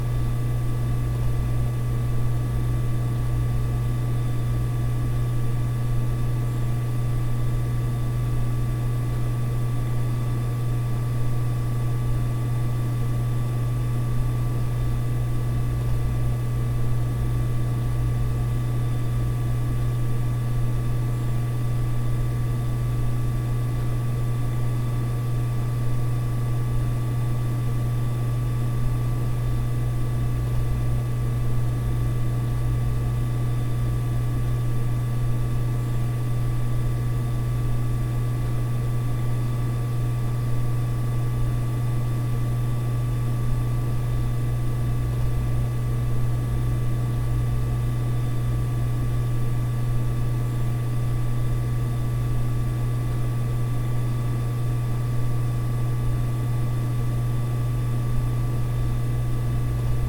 小サーバー室
tiny_server_room.mp3